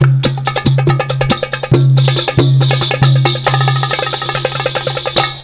PERC 02.AI.wav